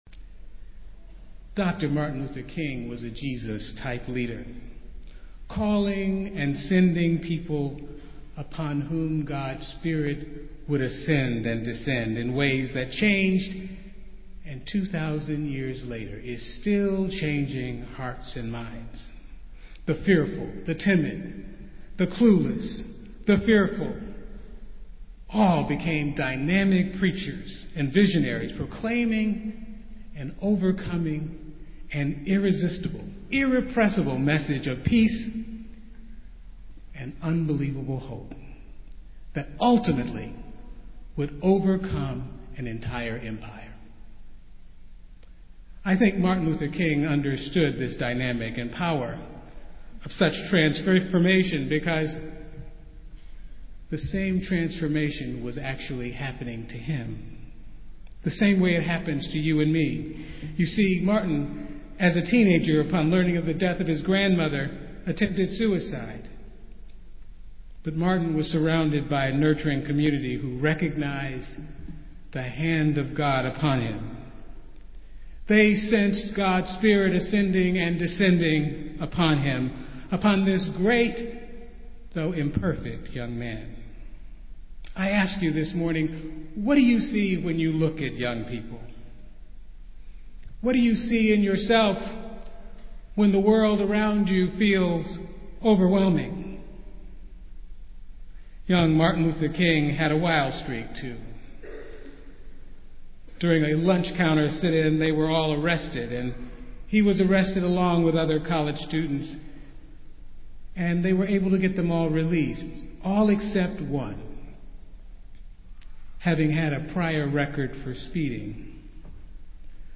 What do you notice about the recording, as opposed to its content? Festival Worship - Martin Luther King Sunday